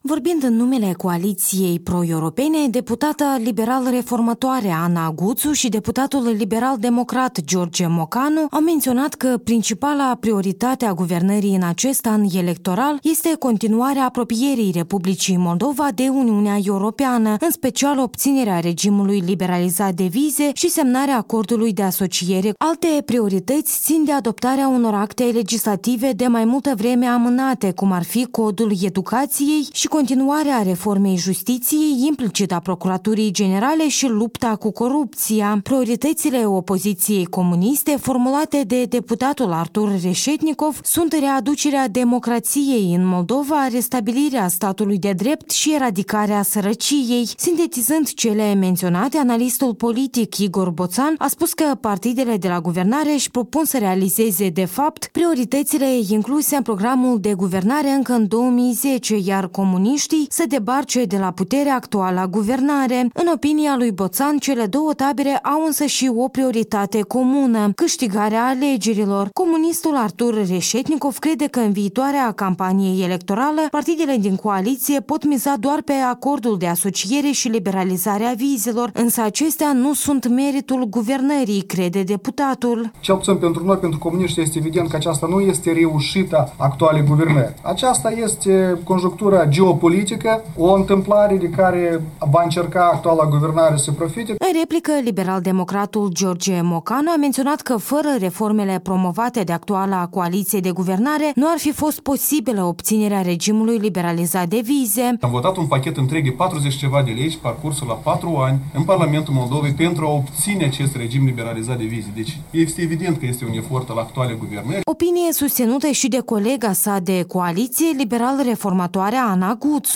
Priorităţile electorale 2014 - o dezbatere la IPN